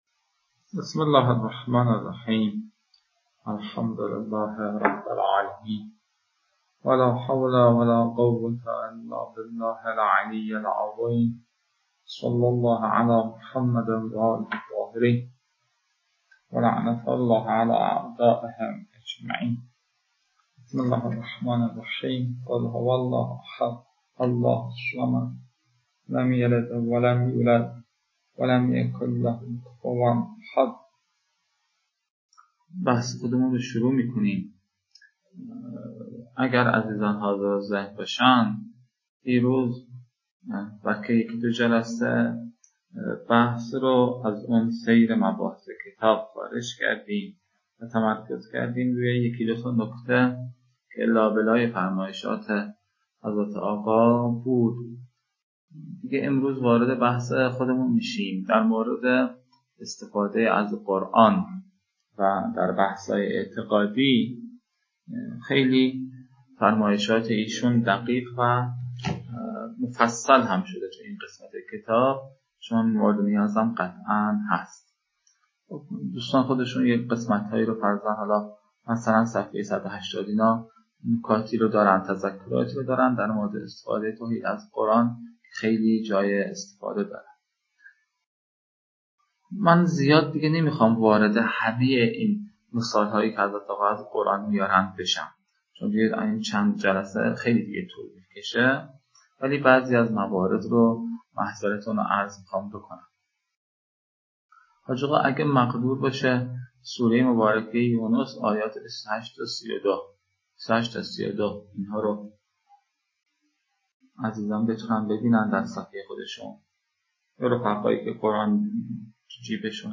🔸 لازم به‌ذکر است که نتیجه این رویکرد، صرف پاره‌ای از بازه کلاس به رفت‌وبرگشت مبحث بین استاد و مخاطبان است که در کنار مجازی برگزارشدن کلاس، حوصله خاصی را در گوش دادن می‌طلبد. (البته فایل‌های صوتی بارها ویرایش شده‌اند تا کیفیت بهتر و مفیدتری داشته باشند.)